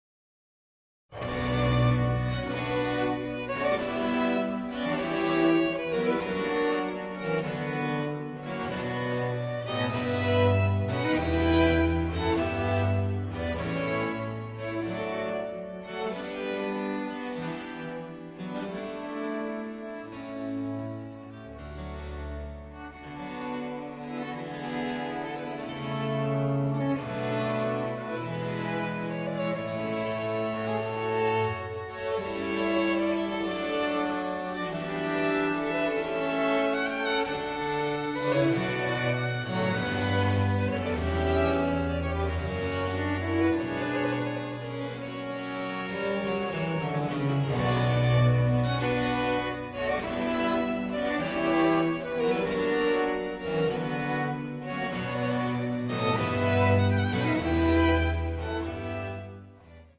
"Beautifully recorded."